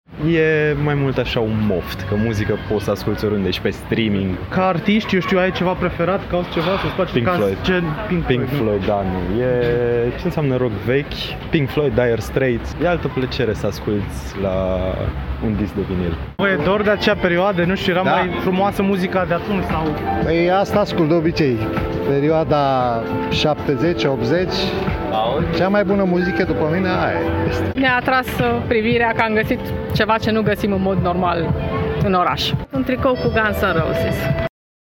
VOX-URI-MUZICA-GAUDEAMUS.mp3